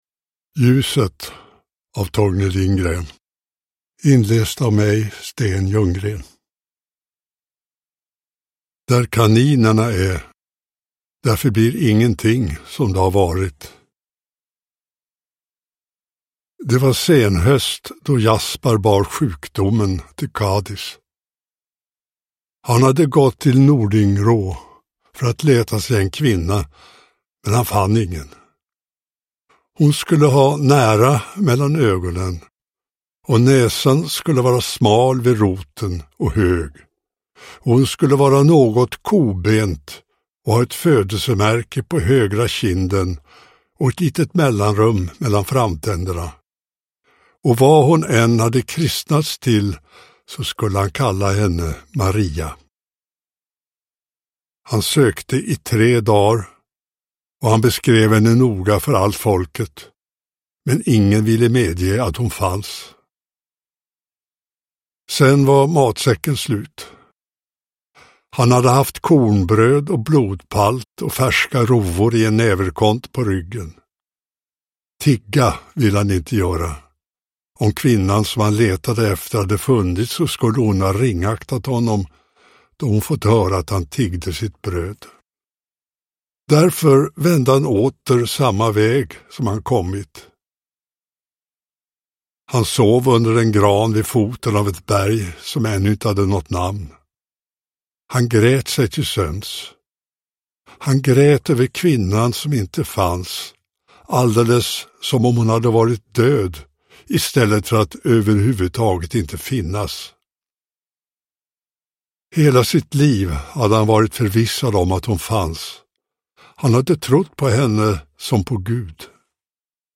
Ljuset – Ljudbok – Laddas ner
Uppläsare: Sten Ljunggren